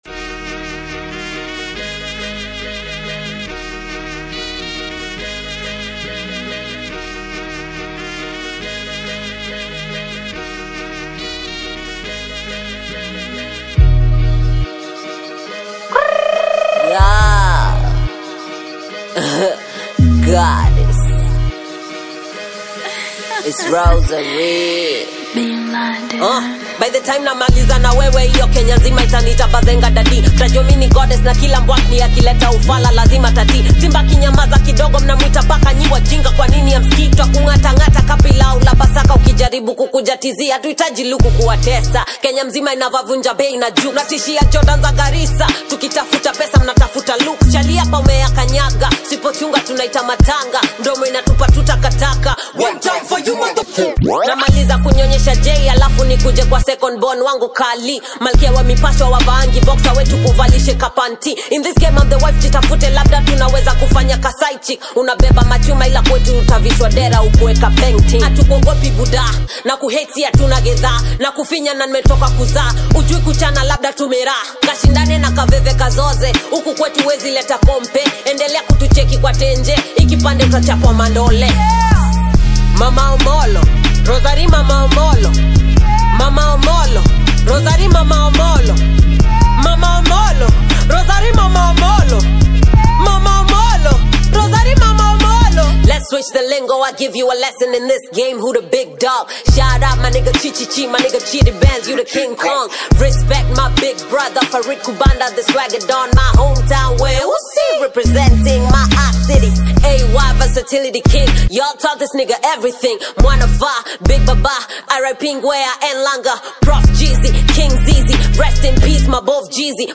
The Tanzanian female rapper
rap song